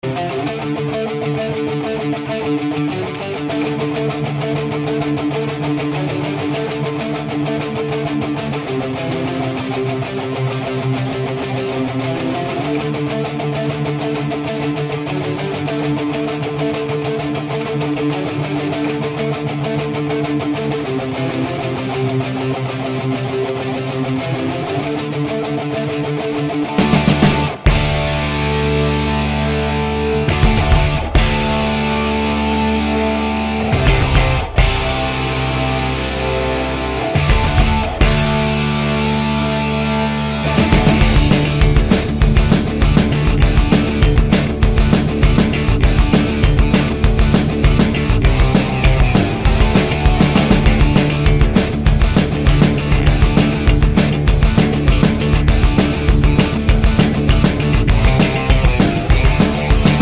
No Intro with Backing Vocals Rock 4:27 Buy £1.50